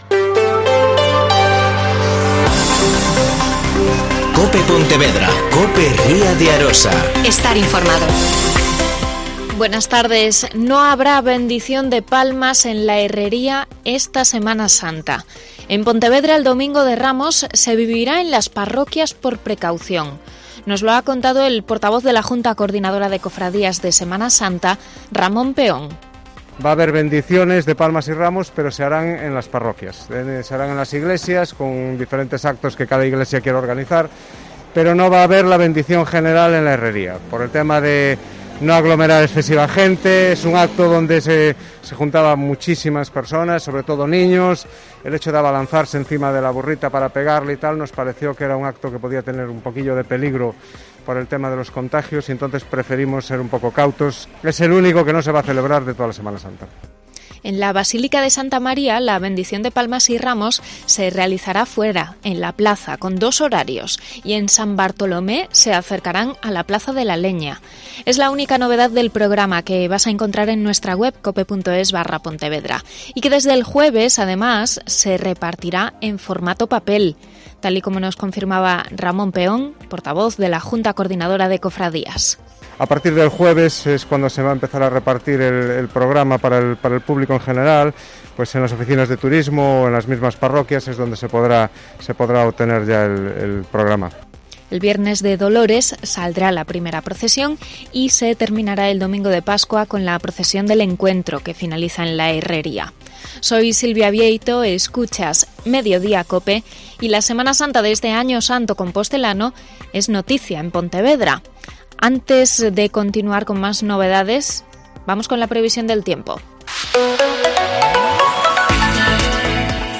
Mediodía COPE Pontevedra y COPE Ría de Arosa (Informativo 14:20h.)